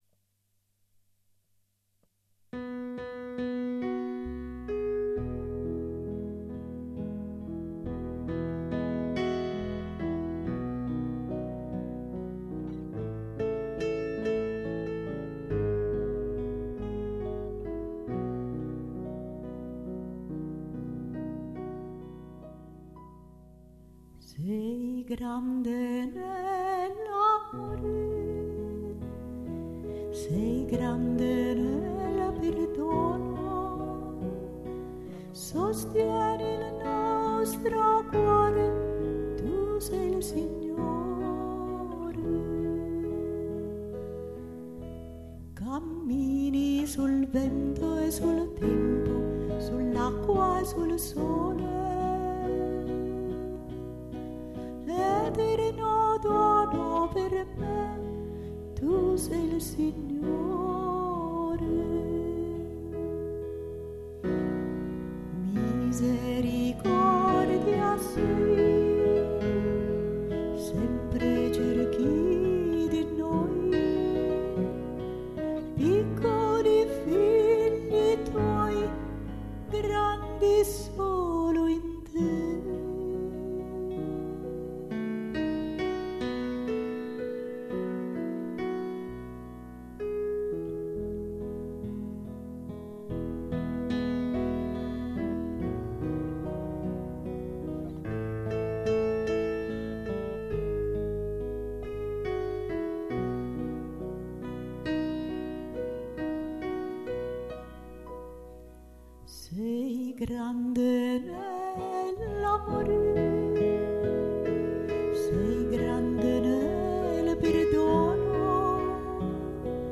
Tu sei il Signore canto mp3 –